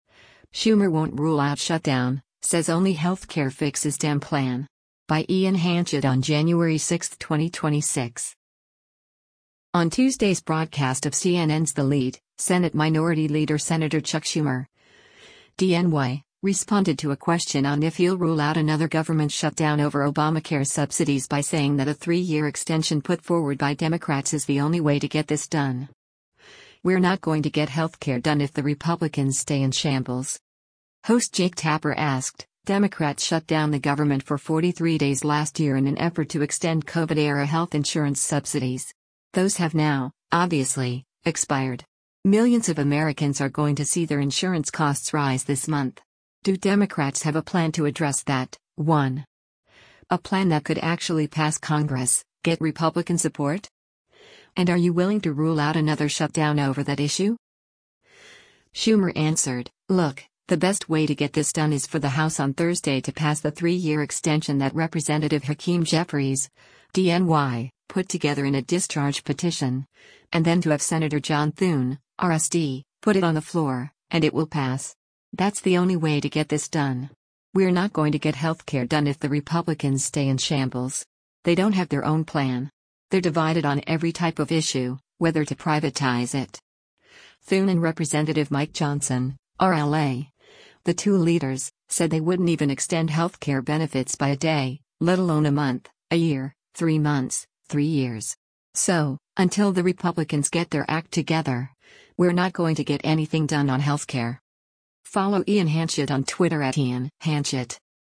On Tuesday’s broadcast of CNN’s “The Lead,” Senate Minority Leader Sen. Chuck Schumer (D-NY) responded to a question on if he’ll rule out another government shutdown over Obamacare subsidies by saying that a three-year extension put forward by Democrats is “the only way to get this done.